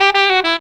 SMALL RIFF.wav